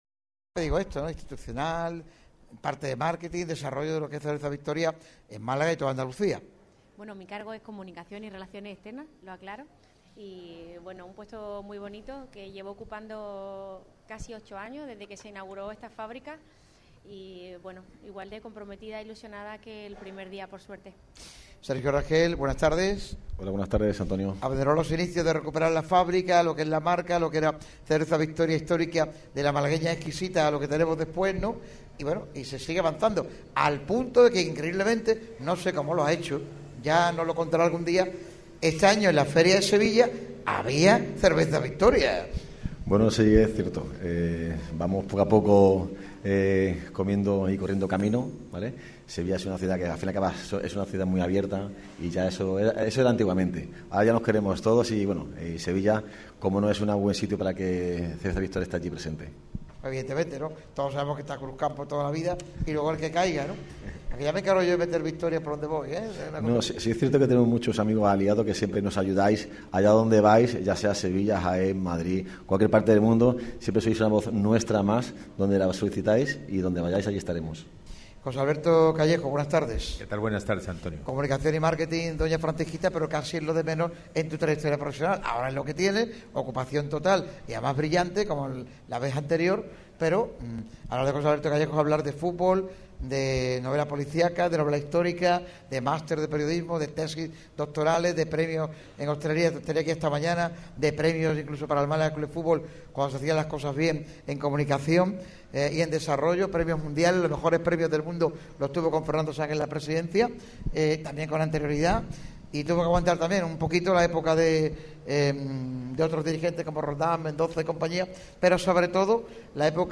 Cervezas Victoria ha acogido uno de esos programas señalados en el calendario de la radio líder del deporte malagueño.